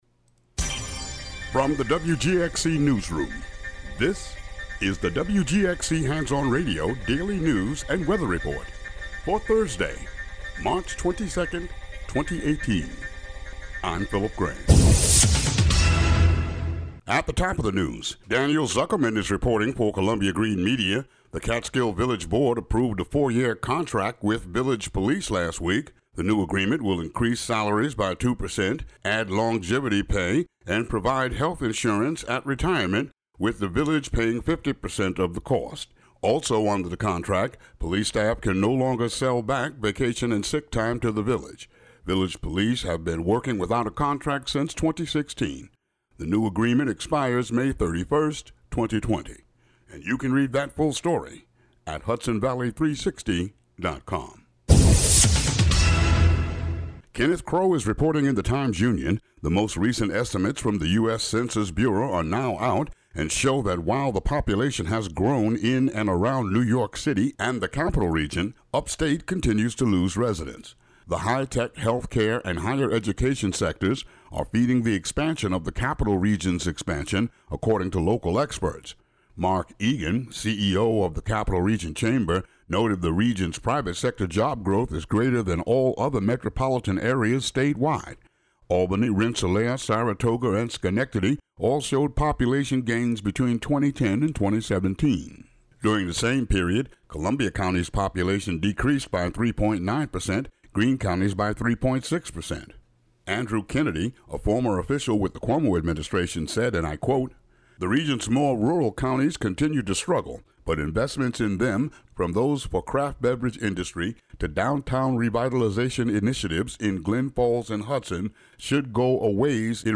The local news for the WGXC listening area.